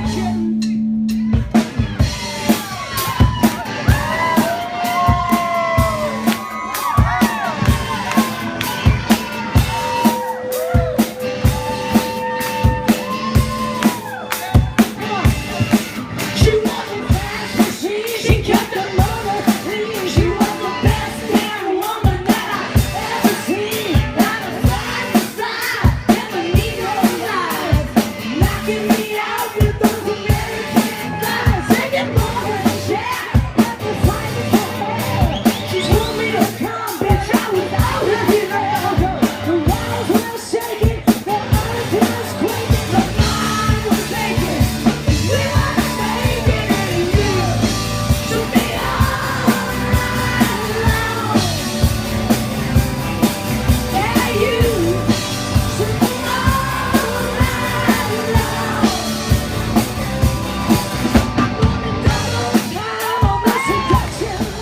(audio captured from youtube video montage)